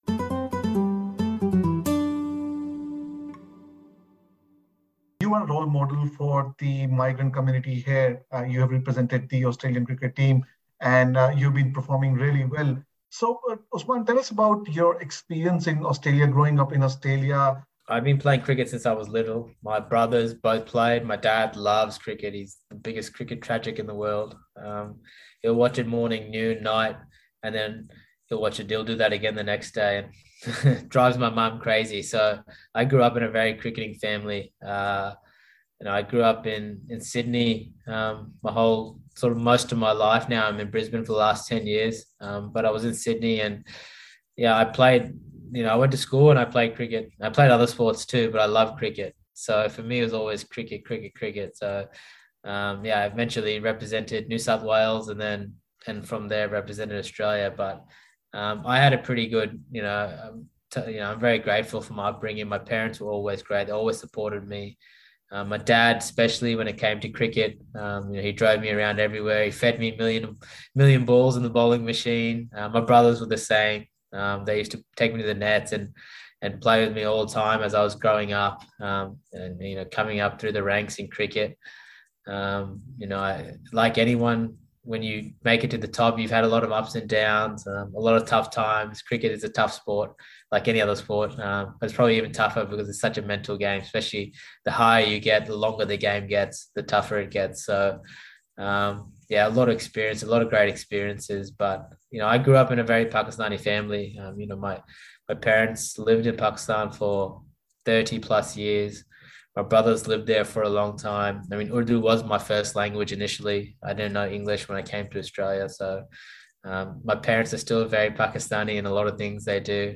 Khawaja said he is resilient and has never let any vilification knock him down. Before leaving to play a Test series in Pakistan, Khawaja told SBS Hindi that Australia has come a long way.